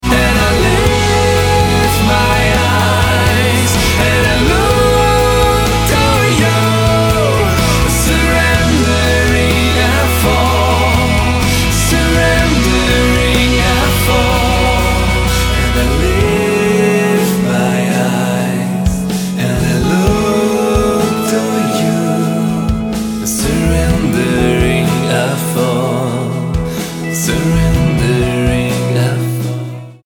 Worship Album